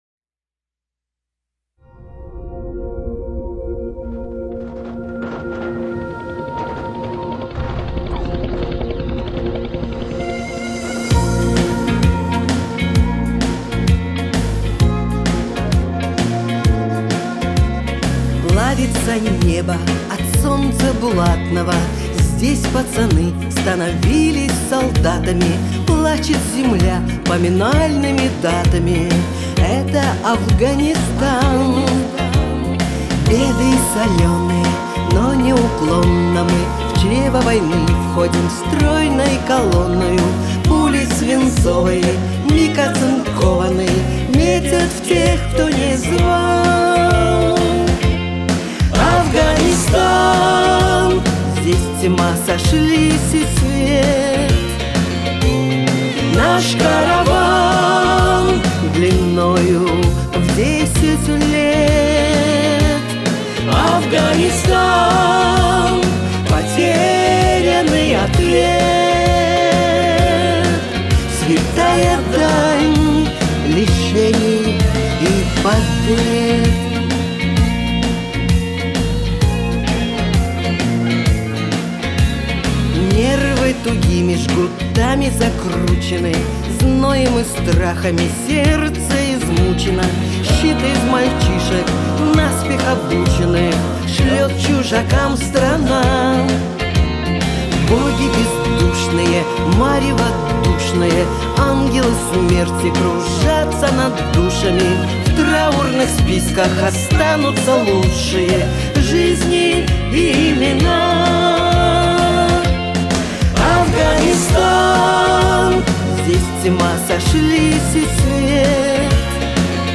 гитары, бэк-вокал